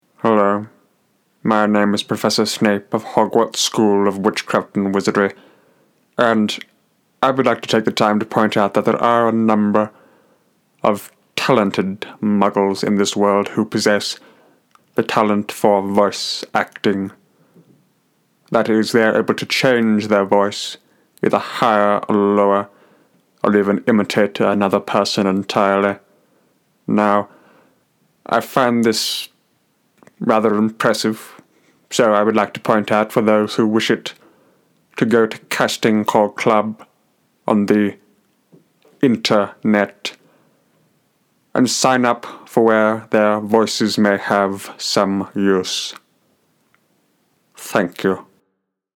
Voice Actor
Kylo Ren